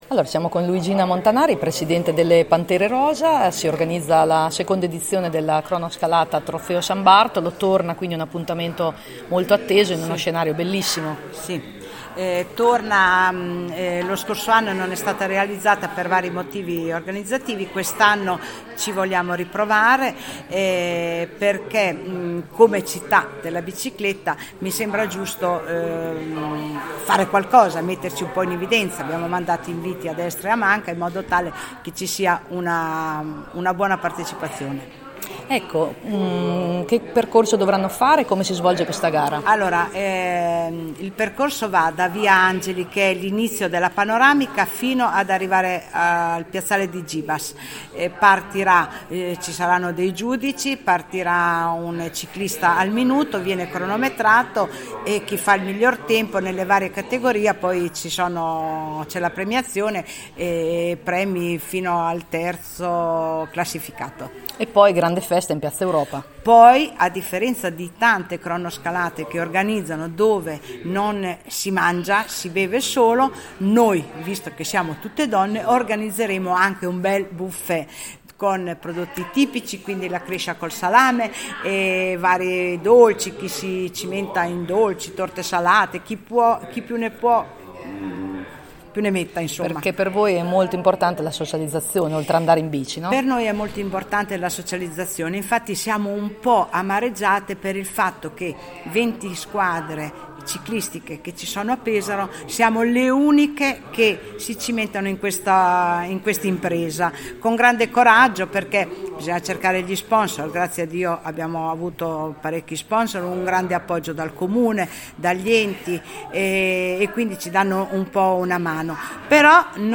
Ai nostri microfoni